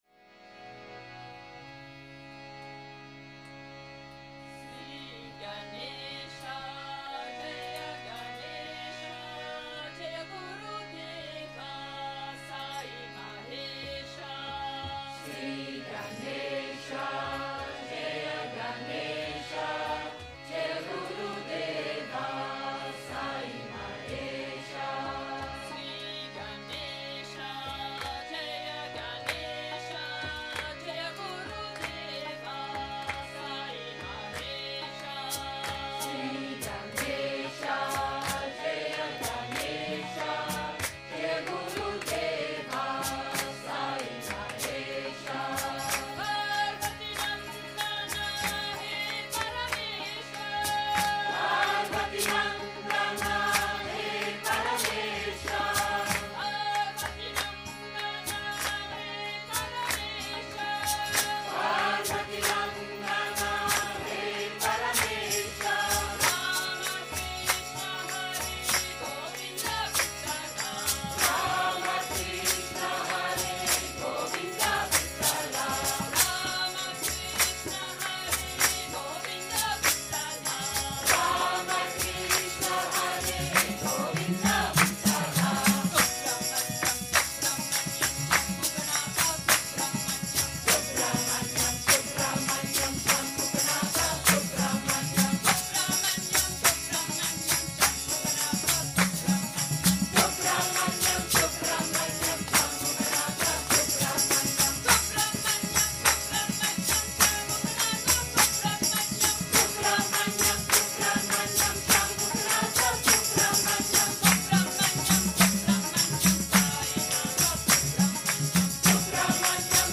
SAI BHAJAN (Click Here For MP3/Real Audio)